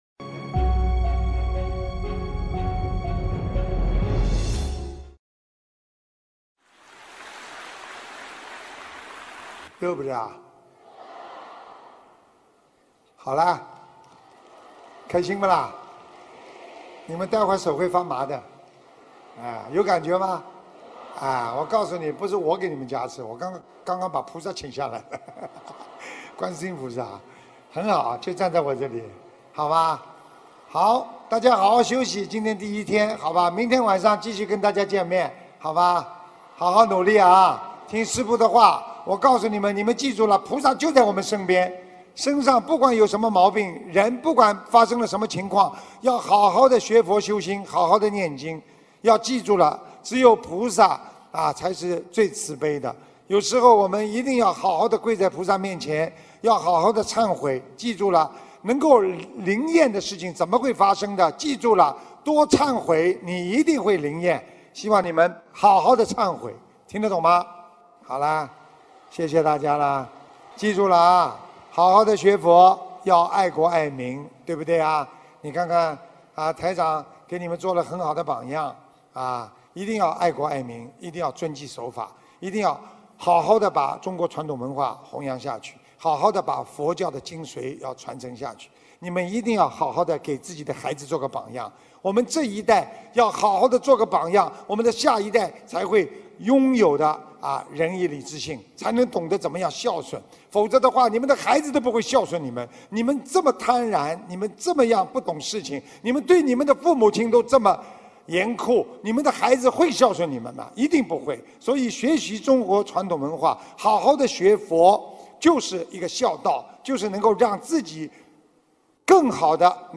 2019年5月9日新加坡世界佛友见面会结束语-经典感人开示节选